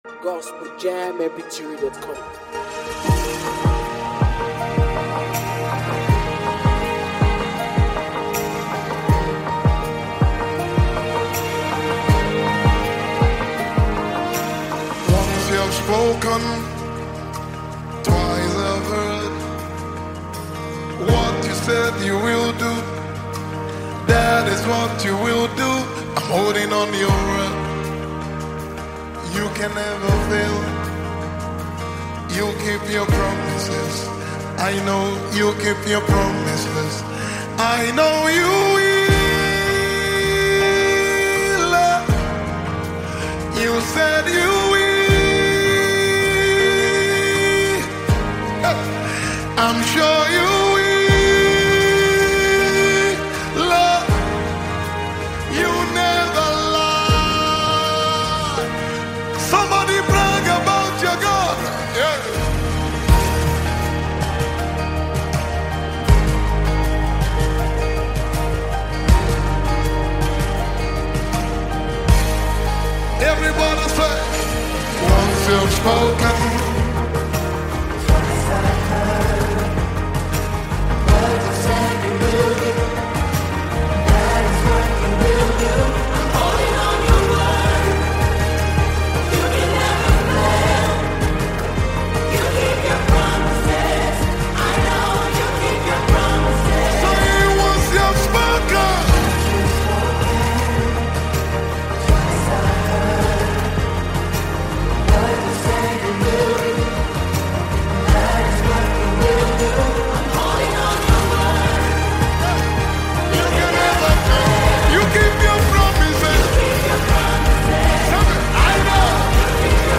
deeply moving worship song
With heartfelt lyrics and an atmosphere soaked in worship